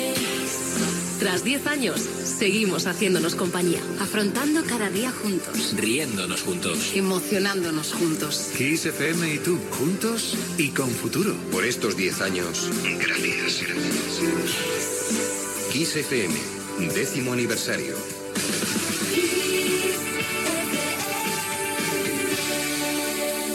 Indicatiu dels 10 anys de l'emissora